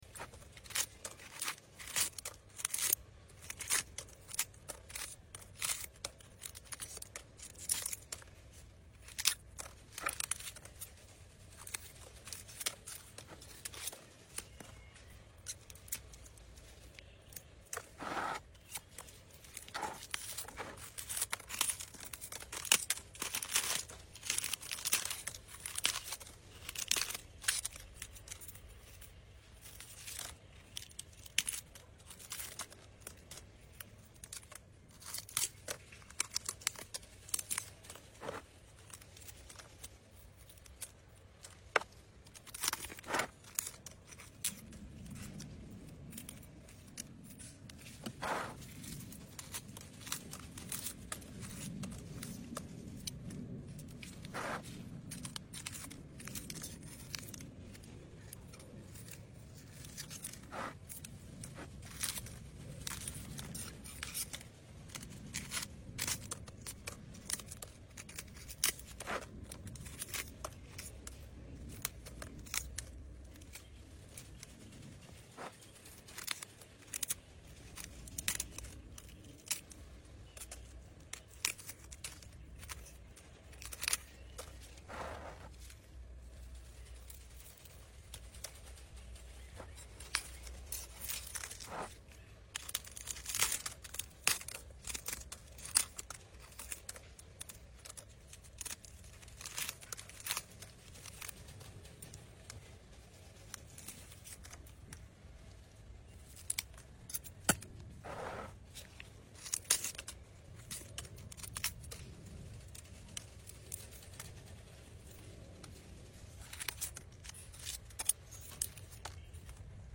Enjoy every satisfying snap and crisp sound for pure relaxation and plant care bliss.